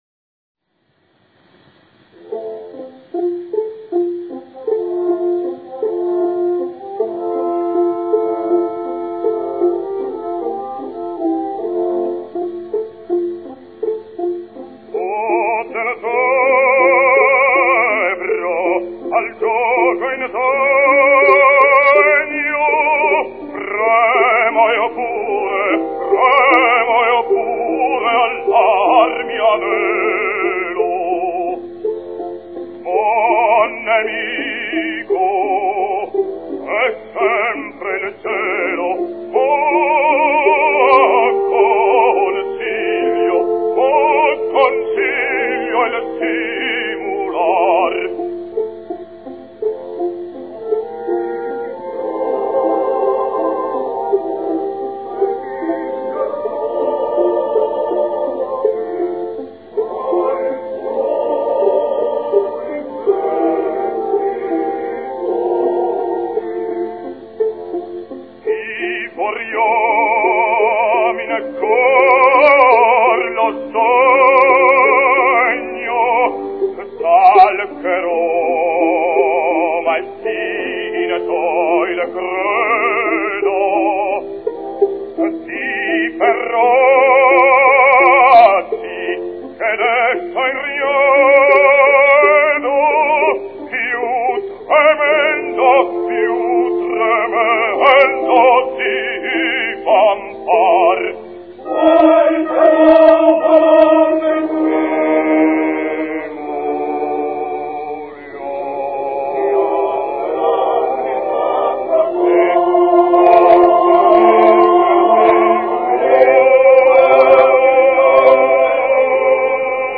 Pinza, Ezio
Without doubt, he was the greatest basso cantante of the 20th century. His voice was truly beautiful, velvet-toned   and of a rare and remarkable flexibility.